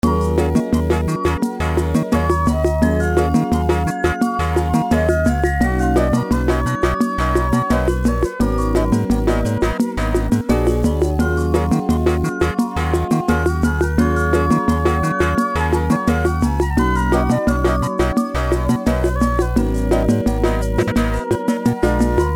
Soon after he came out with another song, Today's Forecast, Elevator and I realized he had used the same piano loop I DID from KagiMusic!!!
Since I had been composing a song using said piano loop, when I first heard it my first thought was "This would totally be some sort of like bossa nova elevator music type of stuff"
So I did just that. made some. bossa. elevator. type shit